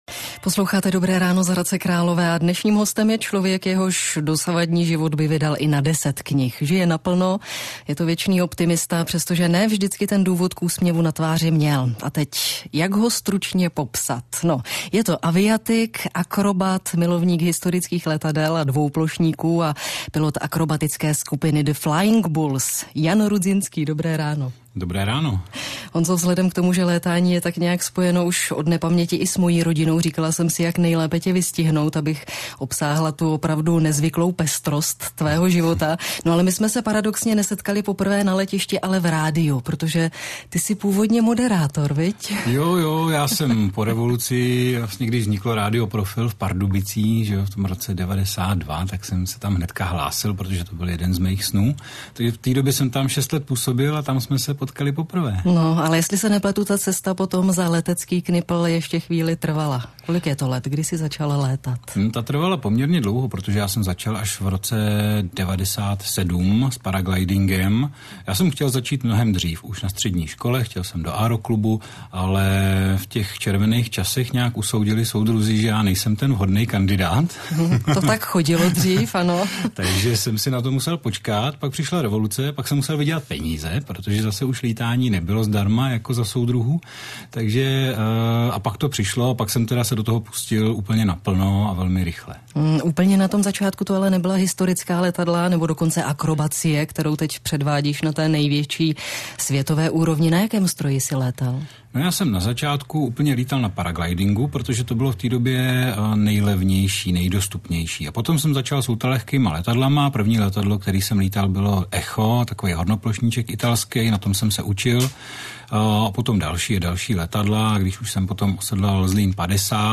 Rozhovor s Lucií Výbornou 25.5.2016 v Radiožurnálu v Českém rozhlase.